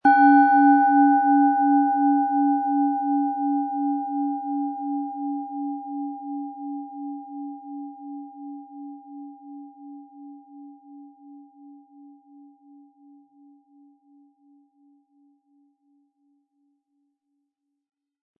Planetenton
MaterialBronze